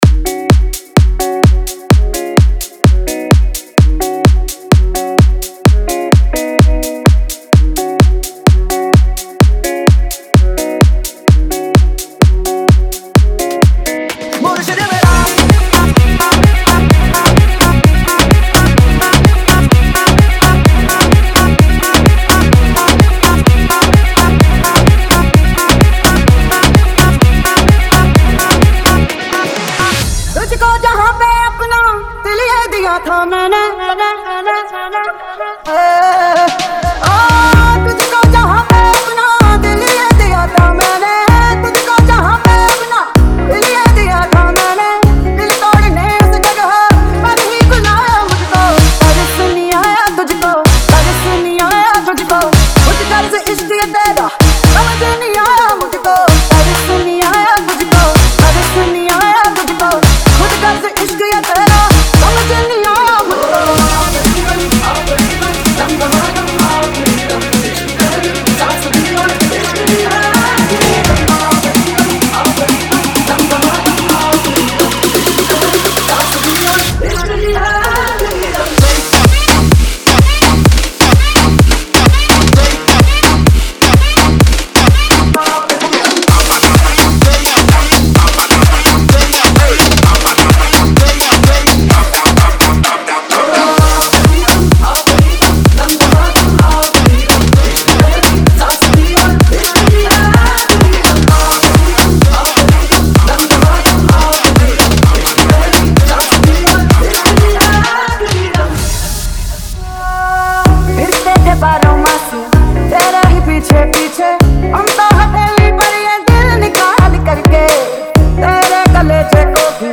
2024 Bollywood Single Remixes Song Name